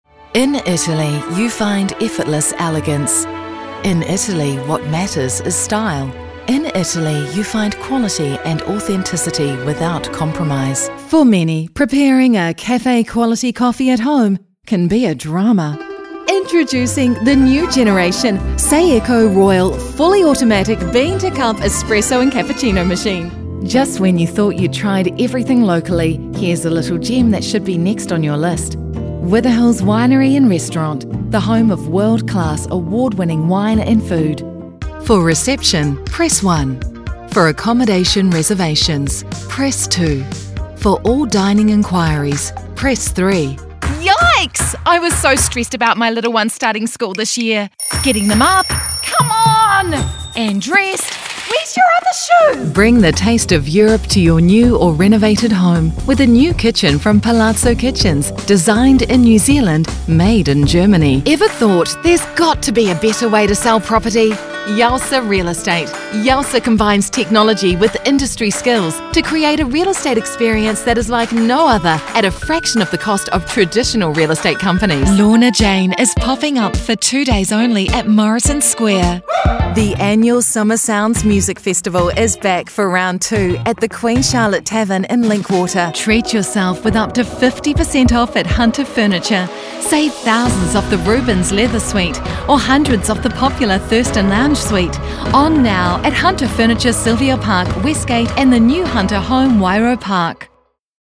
Female Voiceover
voice over artist for over 25 years - with own recording studio